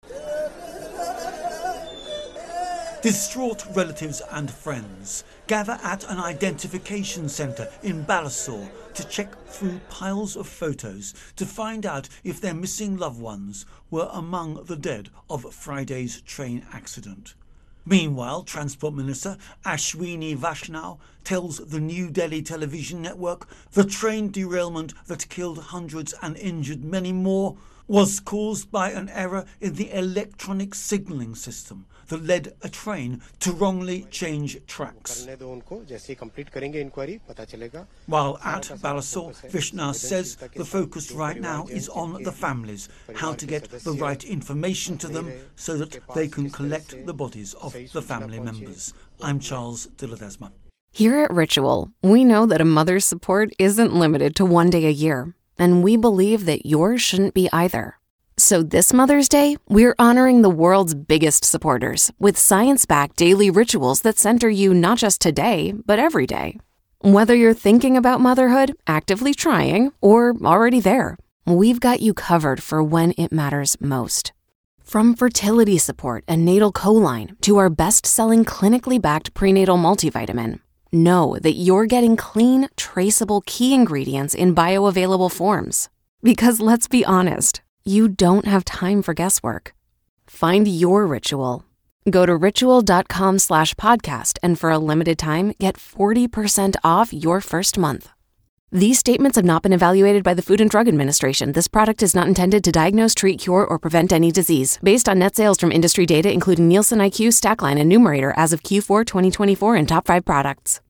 ((opens with nat sound))